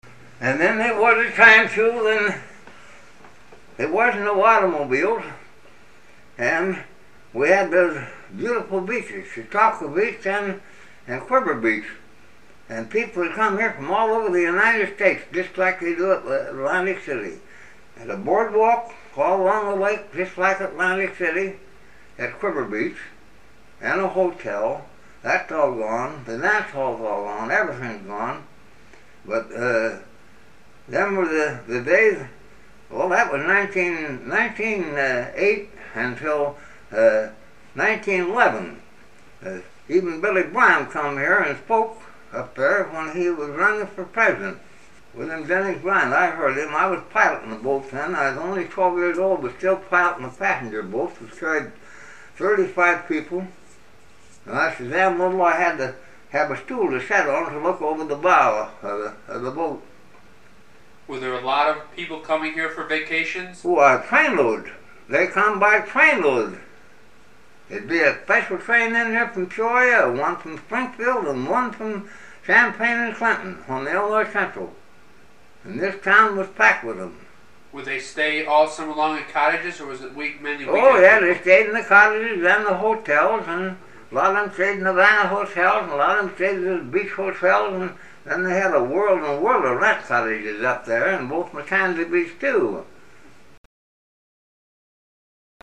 HTR Oral History, 09/02/1